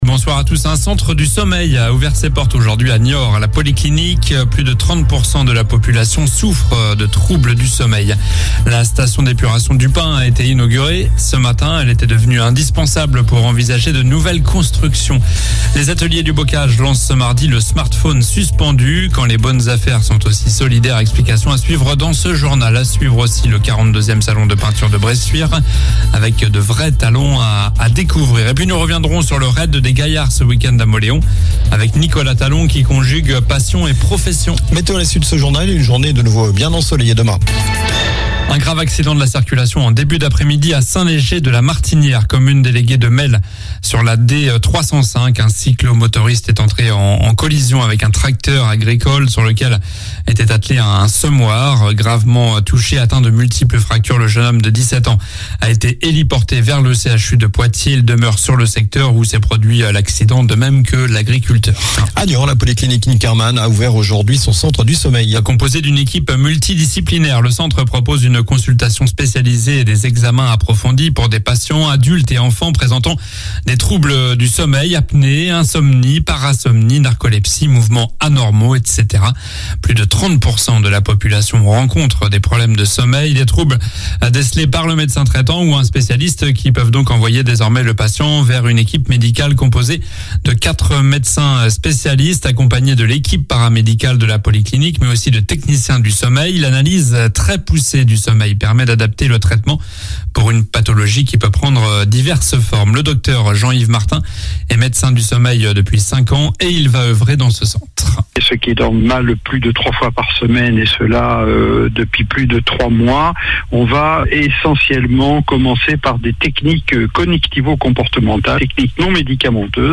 Journal du mardi 02 mai (soir)